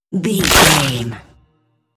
Dramatic hit bloody bone
Sound Effects
heavy
intense
dark
aggressive
hits